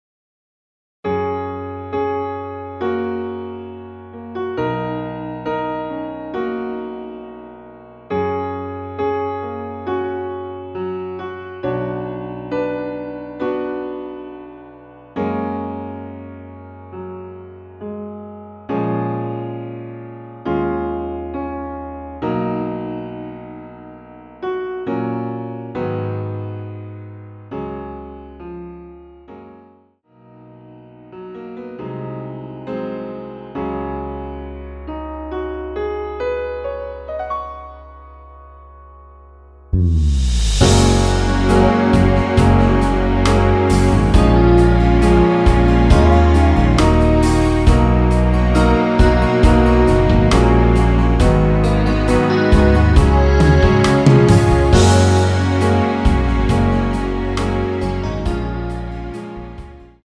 라이브용 버젼2절 없이 진행 됩니다.(아래 동영상및 가사 참조)
F#
앞부분30초, 뒷부분30초씩 편집해서 올려 드리고 있습니다.